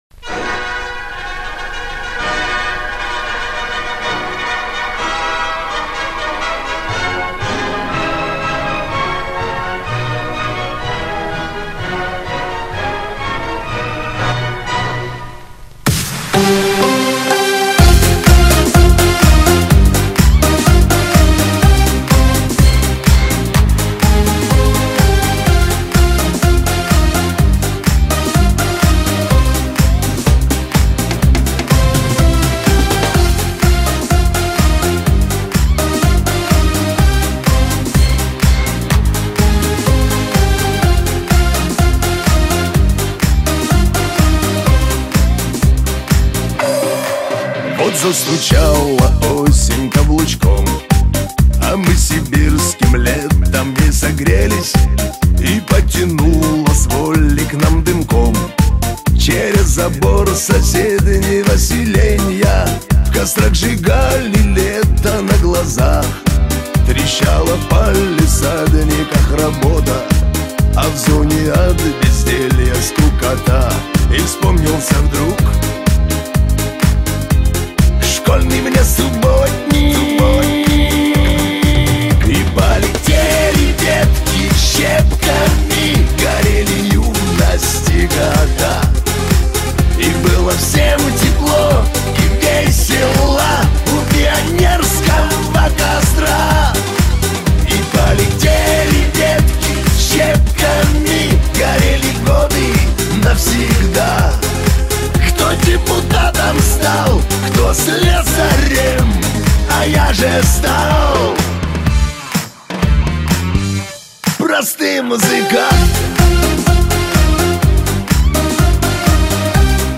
Главная » Музыка » Шансон